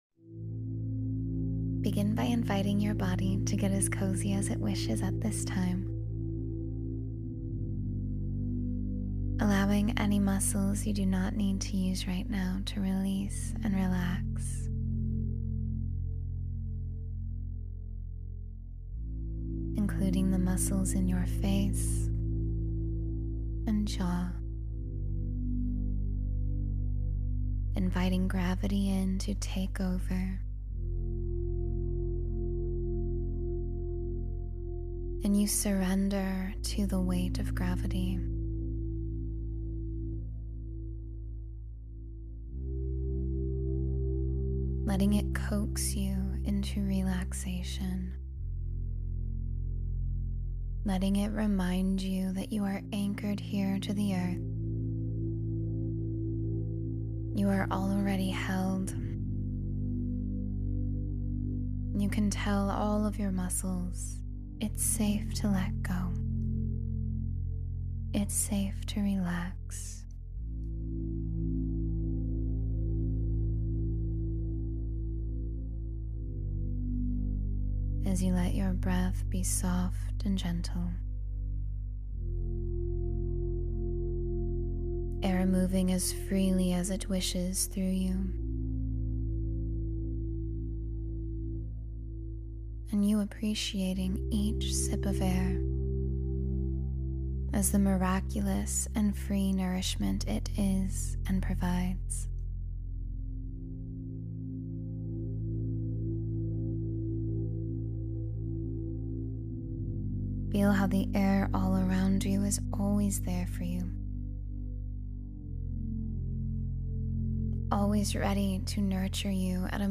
Rediscover Passion and Purpose from Within — Meditation for Self-Discovery